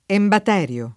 embat$rLo] s. m. (stor.); pl. ‑ri (alla lat. -rii) — es. con acc. scr.: su le tibie intonando embatèri Del vecchio Tirteo [Su lle t&bLe inton#ndo embat$ri del v$kkLo tirt$o] (Pascoli)